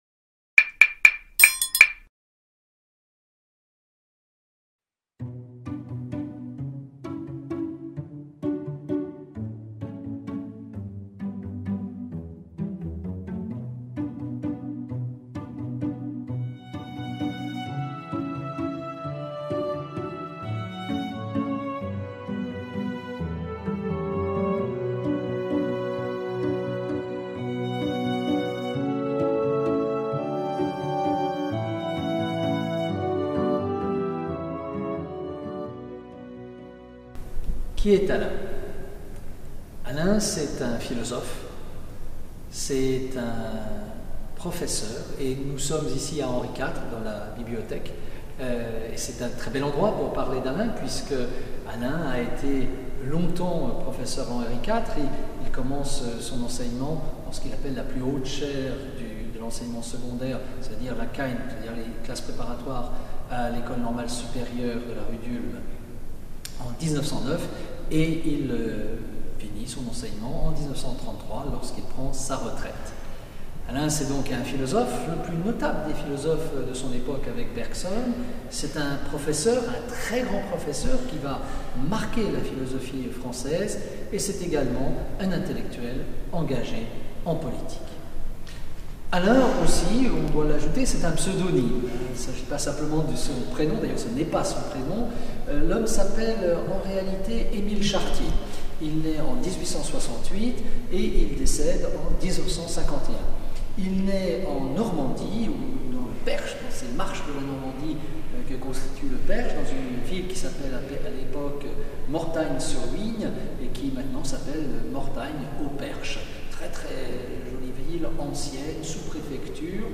Vidéo tournée au lycée Henri-IV, où Alain fut professeur de philosophie en khâgne de 1909 à 1933.